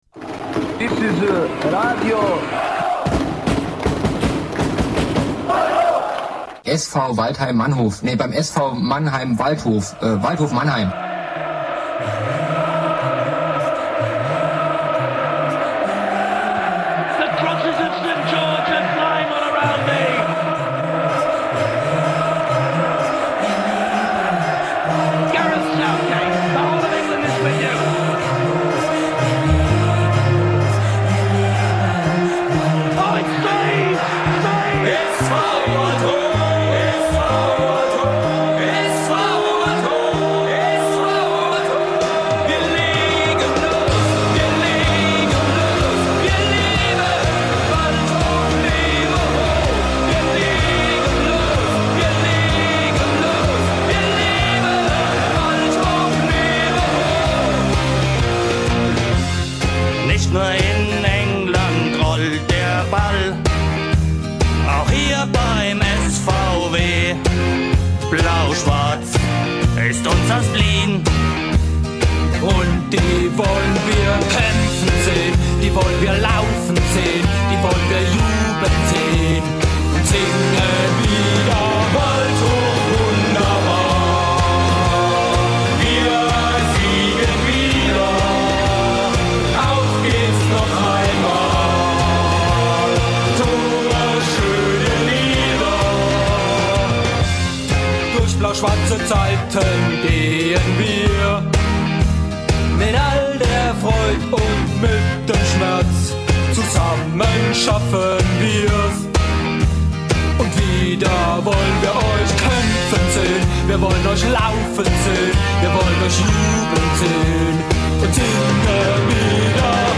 „DoppelPass on Air“. Eine ganze Stunde lang stelltenen sich die beiden Waldhof-Buwe aus dem offensiven Mittelfeld und der Abwehrreihe unserers Drittligateams dabei live im bermuda.funk-Studio 1 unseren und Euren Fragen.